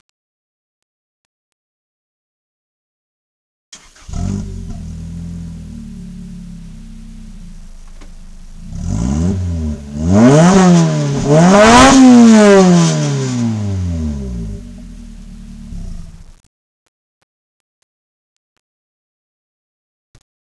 チタンマフラー
・純正レイアウトにより横出しマフラーよりも長くチタンパイプを通過する為、音は正に「チタンの音」。
（ノートPC録音の為、音質が悪いですが参考までに）
カラ吹かし（後方）
fukasi.wav